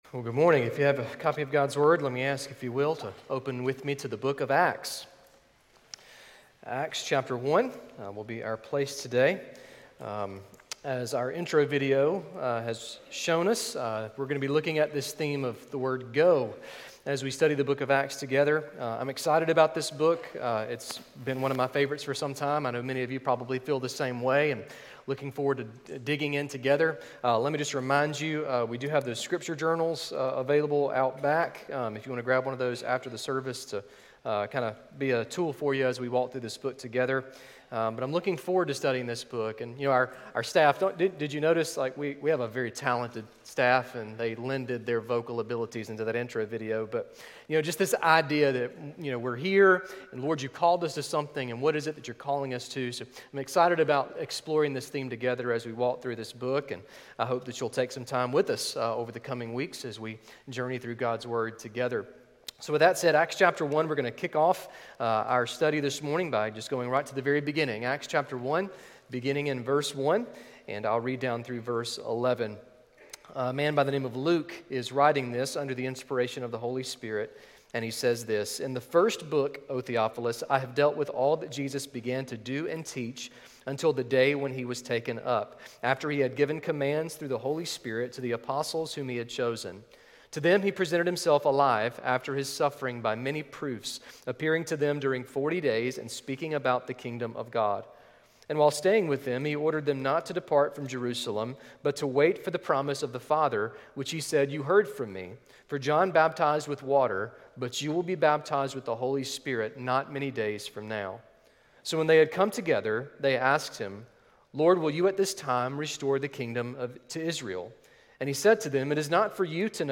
A message from the series "Go."